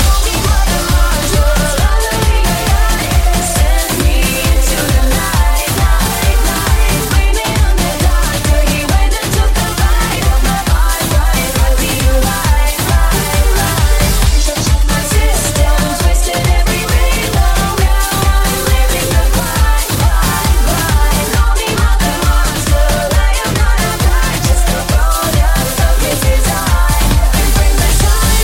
Genere: tribal,anthem,circuit,remix,hit